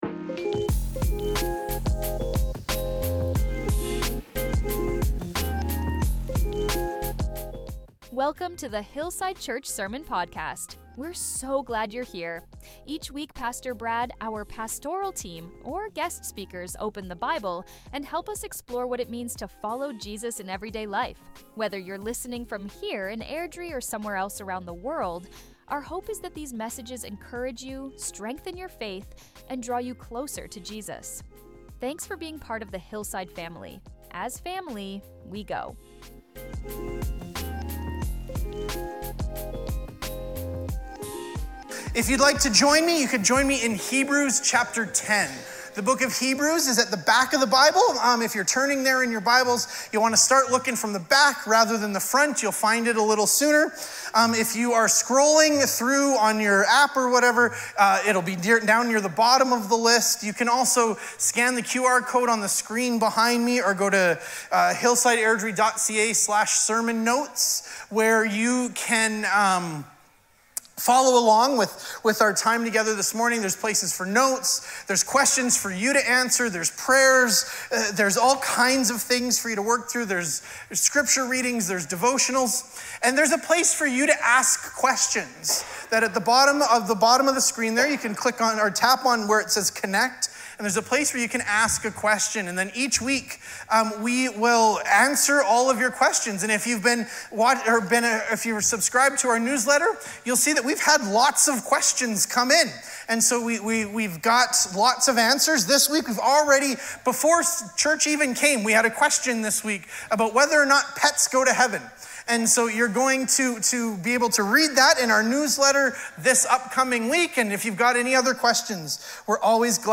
This sermon reminded us that we are not waiting for an invitation—we’ve already been given one.